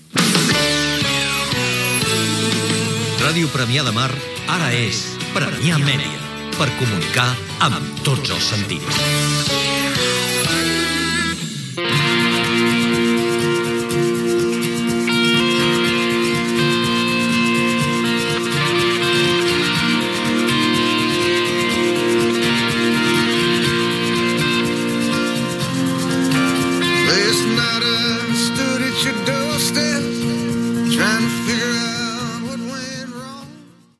Indicatiu amb el nou nom de l'emissora